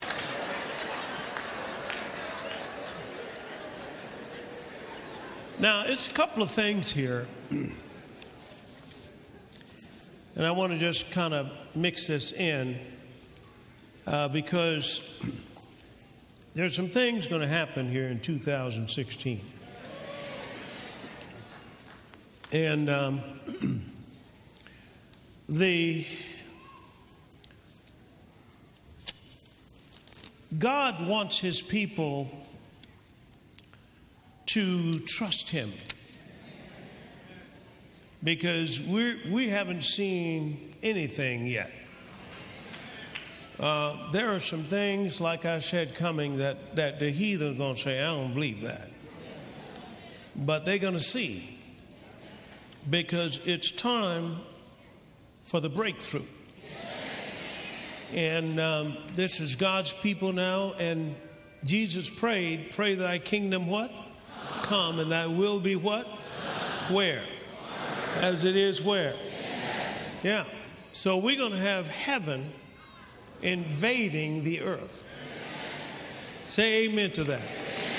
Energize your faith with this timely "breakthrough" message at the 2016 New Year’s Eve Service.